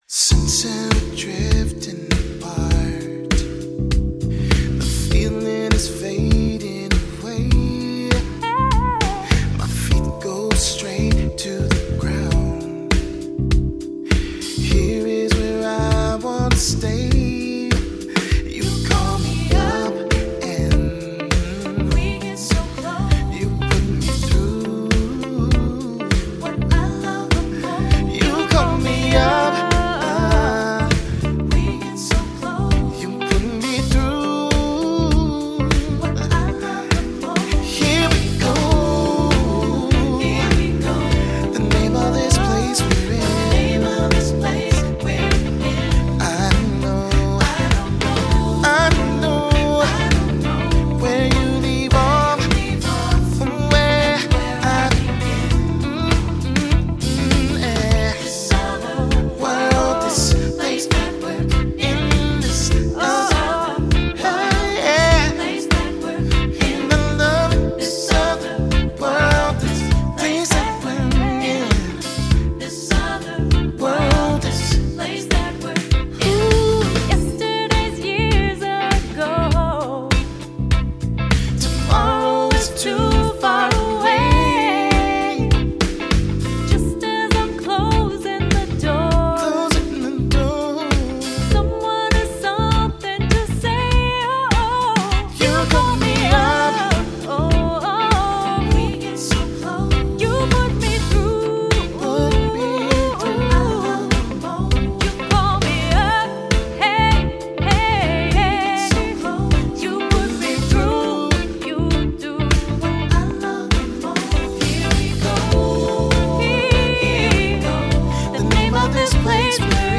"The Name of This Place" (country)